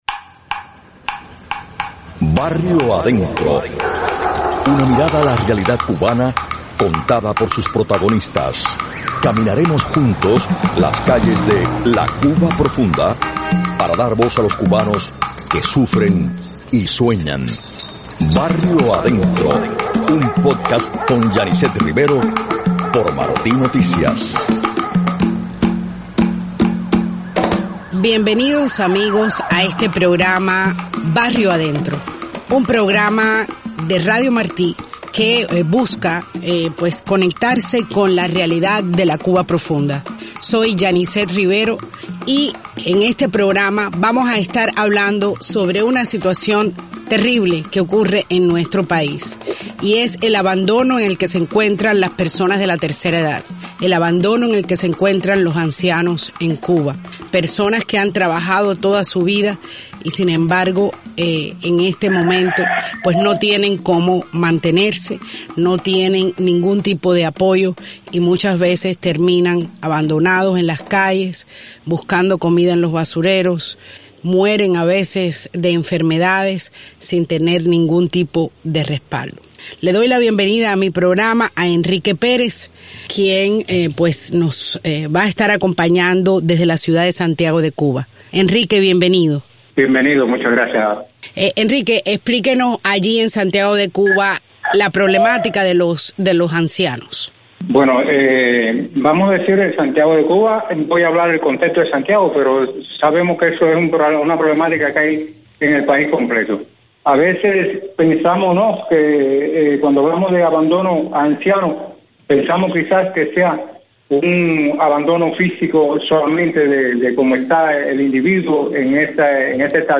Escucharan testimonios desde Santiago y Cienfuegos, además de fragmentos grabados dentro de Cuba por periodistas de la agencia de prensa independiente “Palenque Visión”.